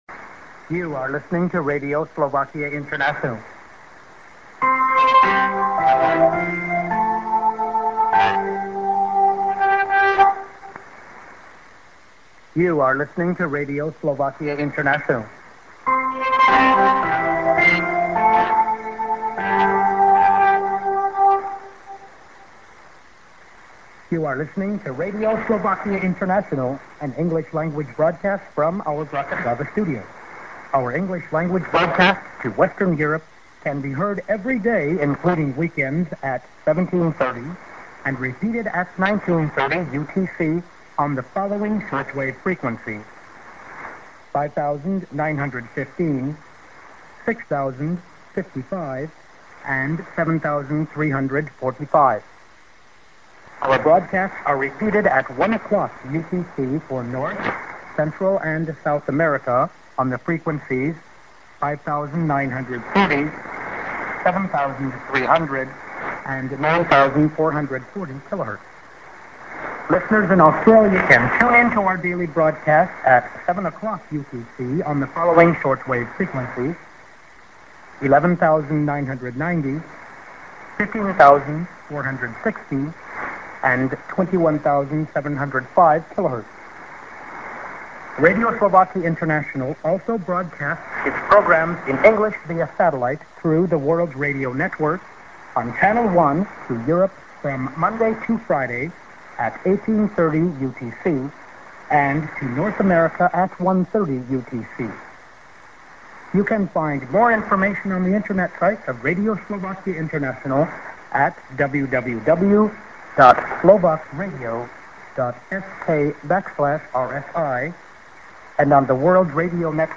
a:　ID(man)+IS:Rep.->ID+SKJ+WebADDR(man)->IS